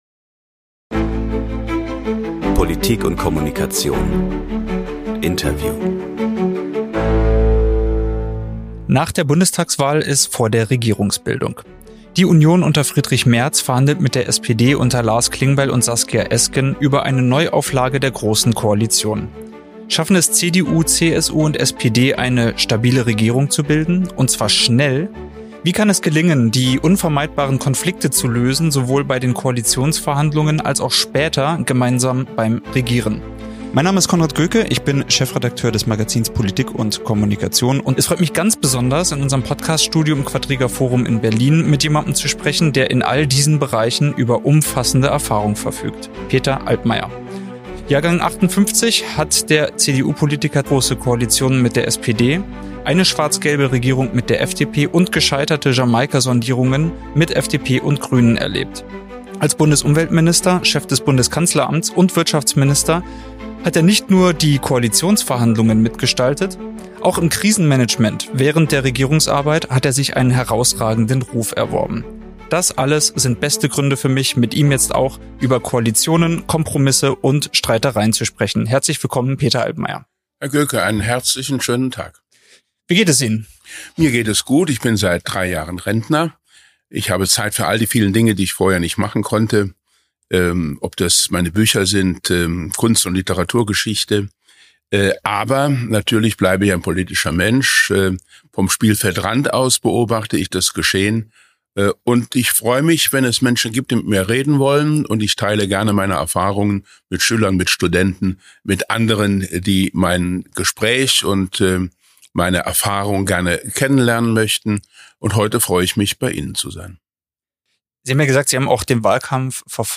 p&k Interview: Peter Altmaier ~ Maschinenraum Podcast